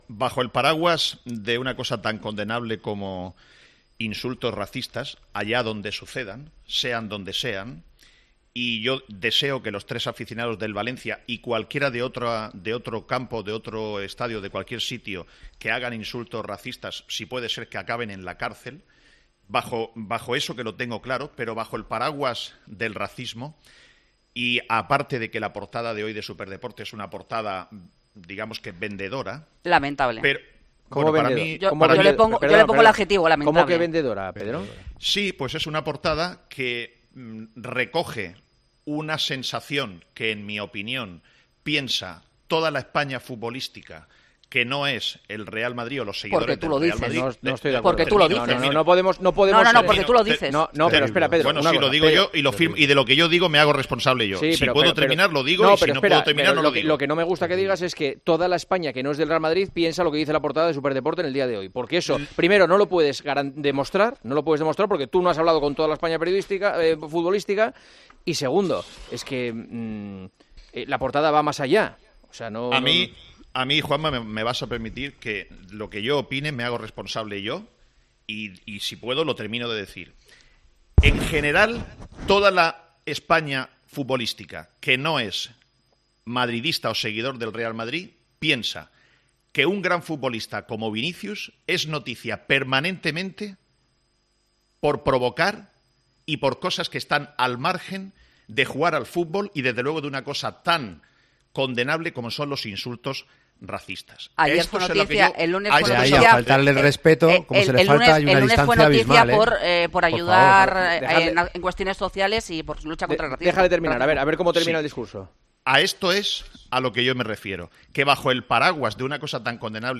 El director de El Partidazo de COPE habló de la polémica en Valencia y sus críticas a Vinicius después de la polémica de la pasada temporada en Mestalla.